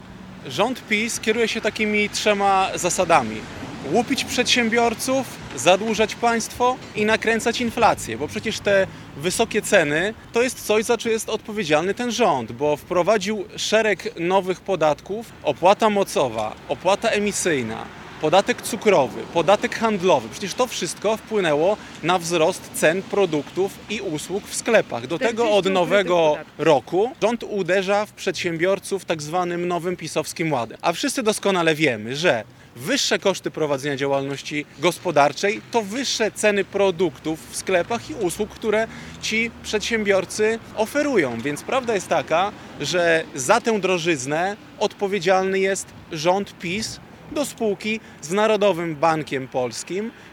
Politycy opozycji obwiniają rząd za tę drożyznę. O postępujących podwyżkach cen mówi Arkadiusz Marchewka z Platformy Obywatelskiej.